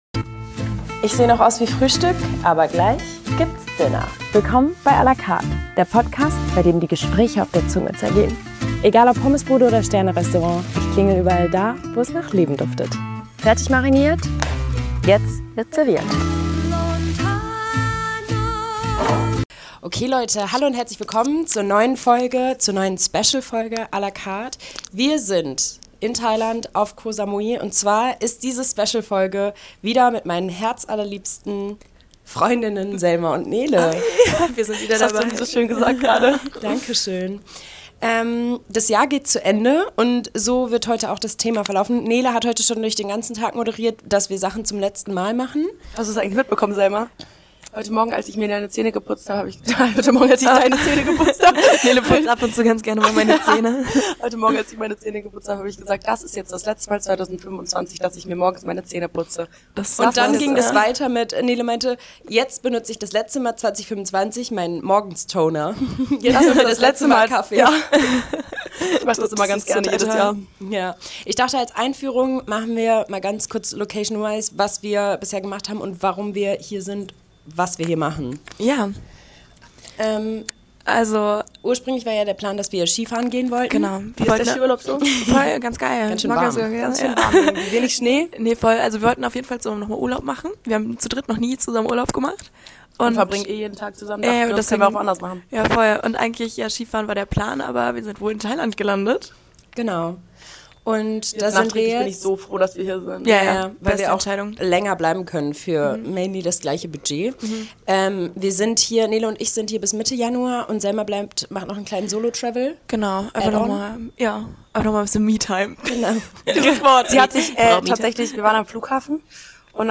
Zwischen Palmen und Sonne sprechen die Drei auf Ko Samui über gute, aber auch unnötige Neujahrsvorsätze.